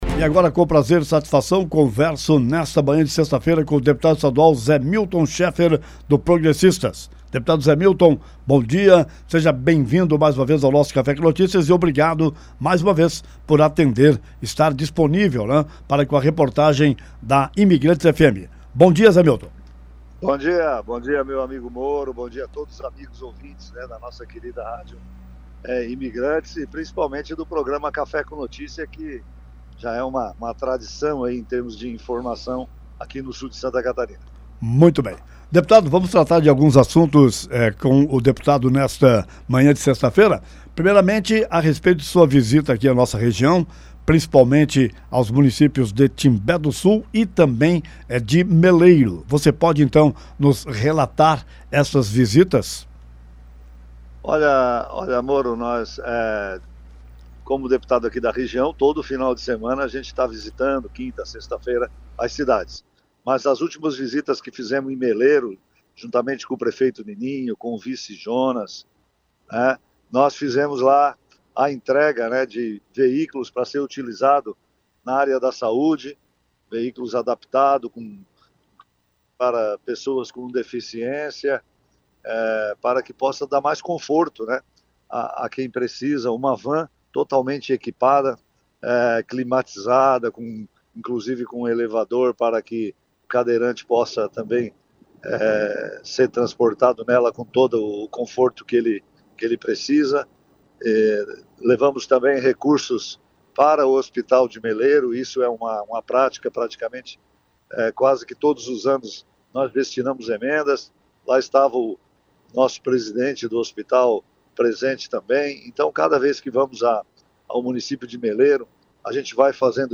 Deputado Zé Milton realiza roteiro pela região e faz entrega em municípios: Ouça a entrevista concedida nesta sexta-feira (27/02) no Programa Café com Notícias: